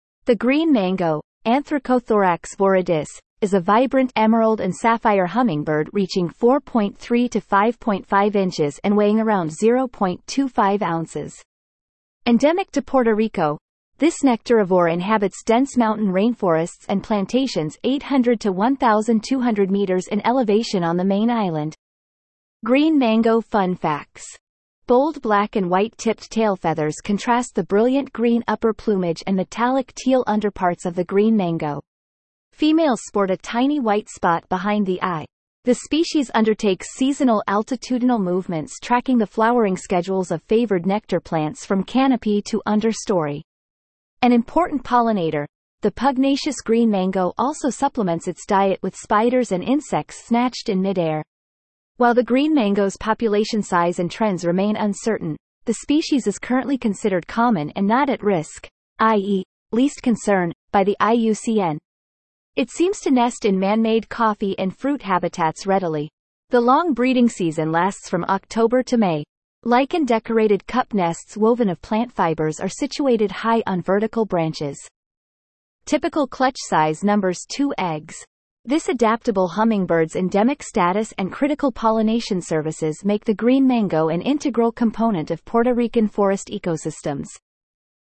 Green Mango Hummingbird
Green-Mango-Hummingbird.mp3